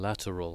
lateral.wav